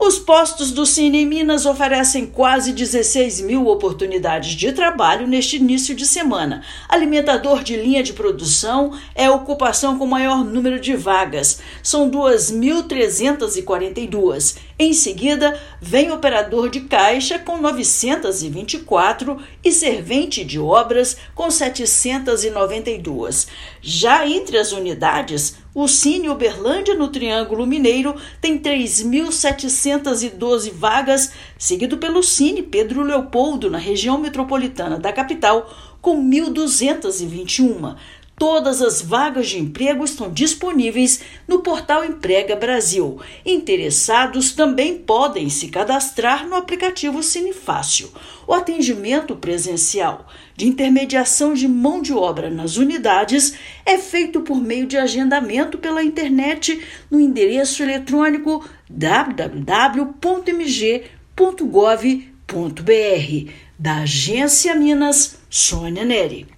As funções com maior número de oportunidades são alimentador de linha de produção e operador de caixa. Ouça matéria de rádio.